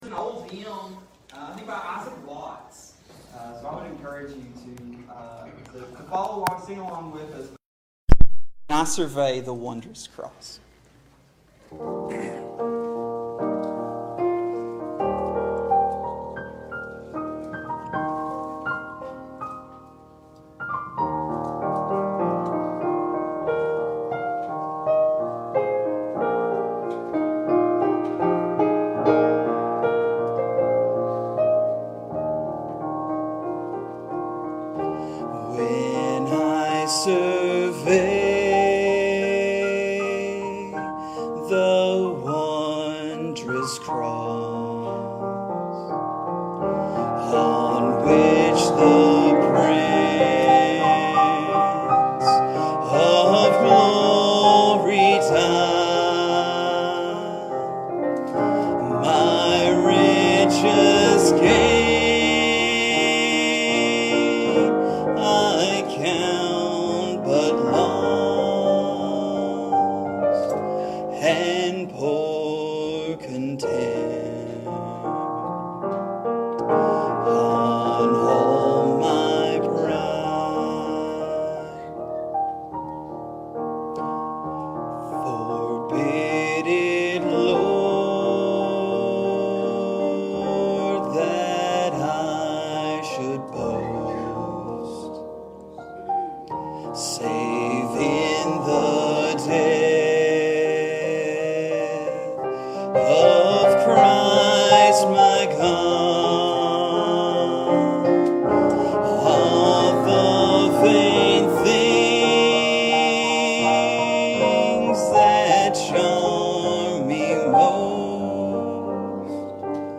Wars and Rumors of Wars | SermonAudio Broadcaster is Live View the Live Stream Share this sermon Disabled by adblocker Copy URL Copied!